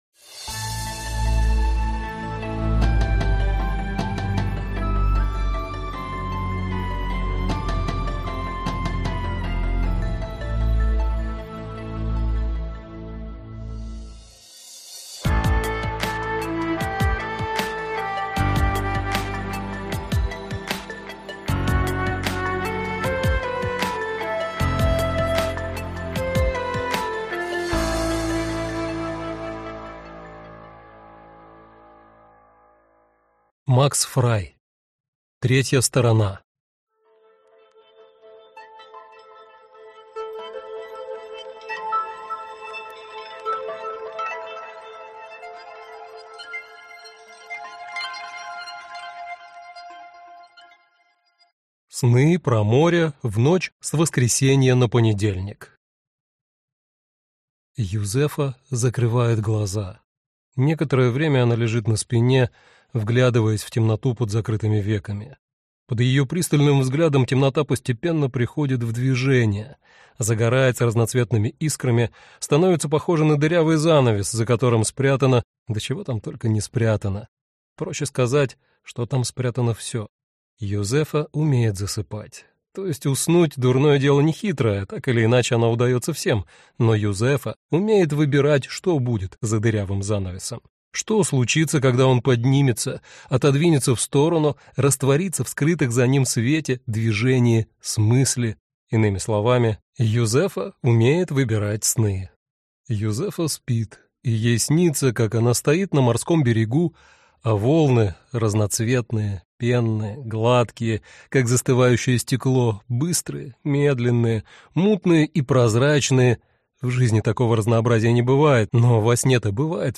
Аудиокнига Третья сторона - купить, скачать и слушать онлайн | КнигоПоиск